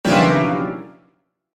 Evil Scary Walk Sound Button - Free Download & Play